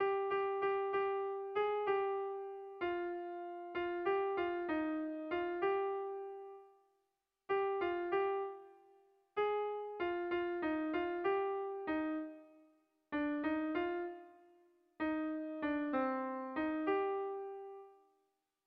Dantzakoa
Lauko txikia (hg) / Bi puntuko txikia (ip)
AB